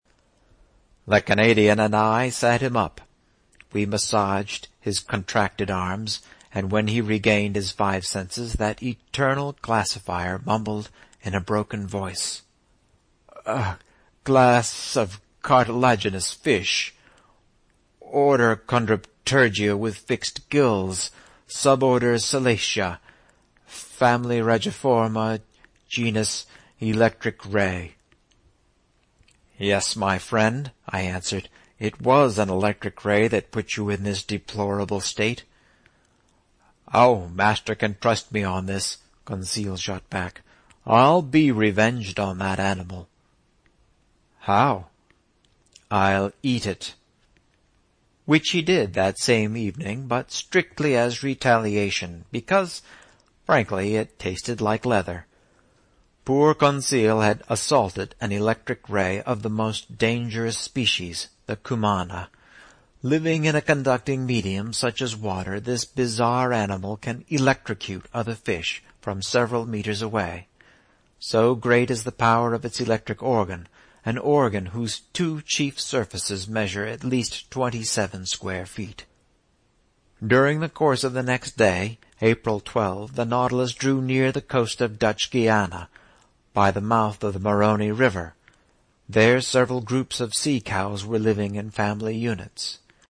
在线英语听力室英语听书《海底两万里》第488期 第30章 从合恩角到亚马逊河(12)的听力文件下载,《海底两万里》中英双语有声读物附MP3下载